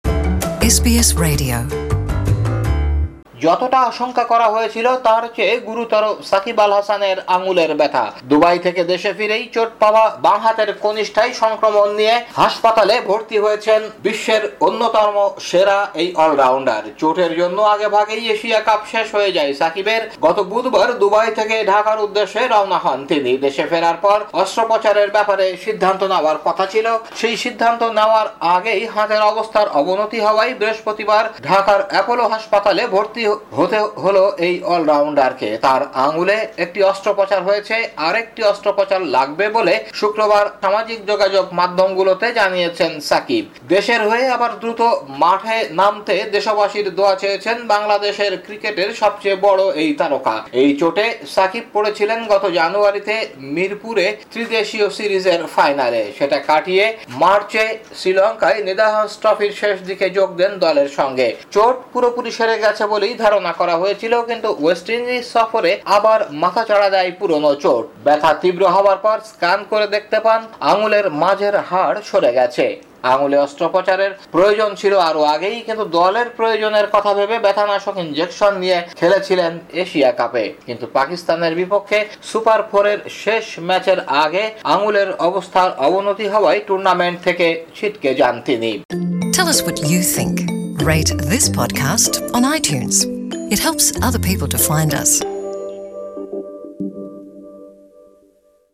প্রতিবেদনটি বাংলায় শুনতে উপরের অডিও প্লেয়ারে ক্লিক করুন।